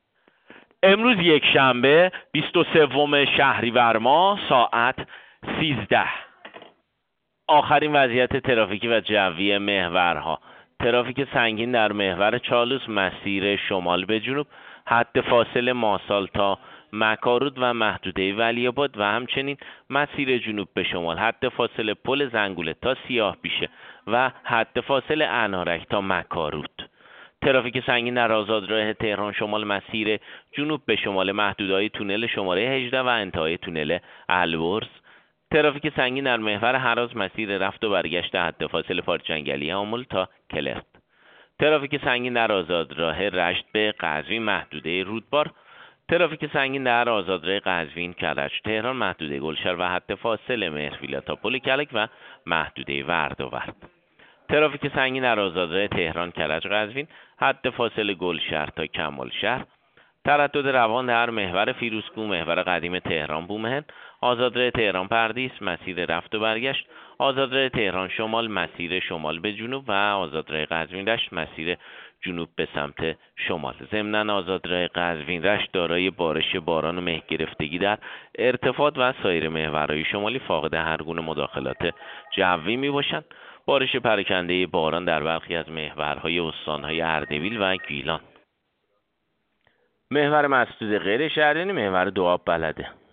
گزارش رادیو اینترنتی از آخرین وضعیت ترافیکی جاده‌ها ساعت ۱۳ بیست و سوم شهریور؛